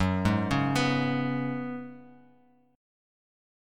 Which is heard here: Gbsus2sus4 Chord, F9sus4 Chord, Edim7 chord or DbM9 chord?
Gbsus2sus4 Chord